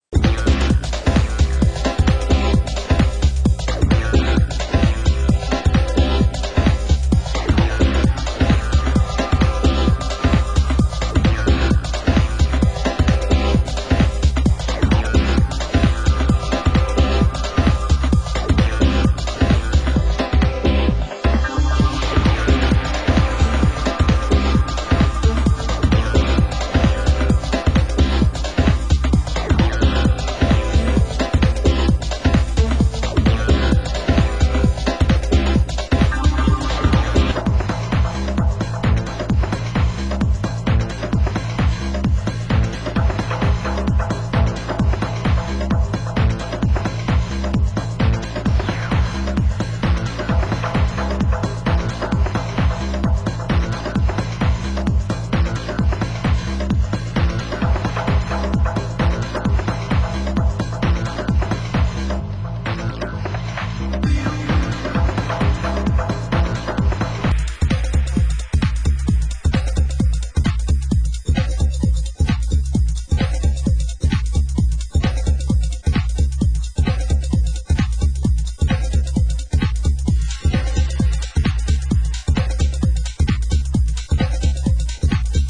Genre: Tech House